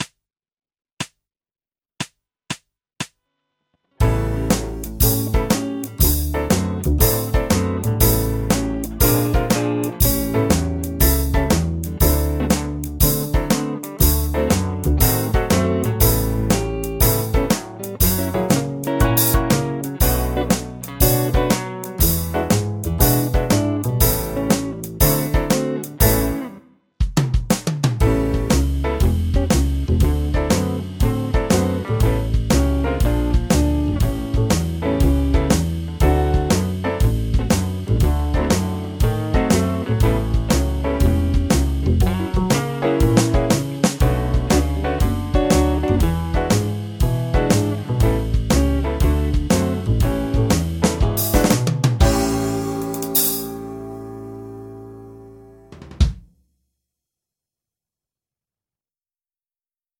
ブルーノート・スケール ギタースケールハンドブック -島村楽器